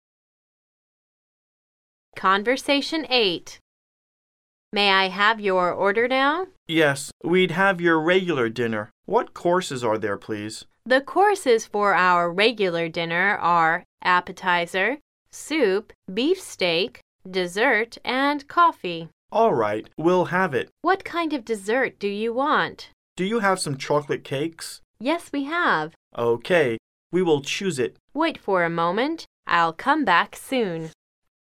Conversation 8